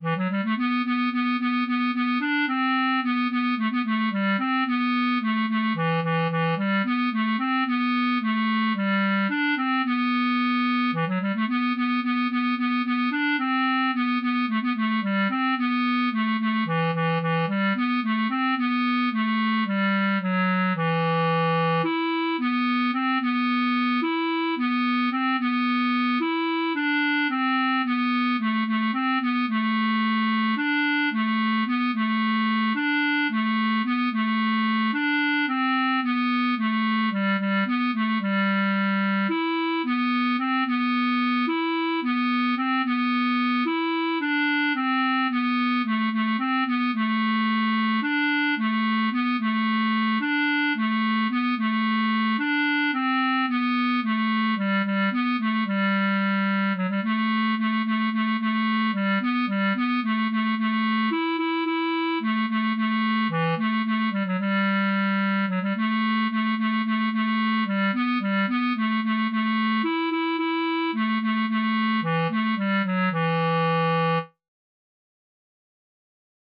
Jewish Folk Song (Chabad-Lubavitch melody)
E minor ♩= 110 bpm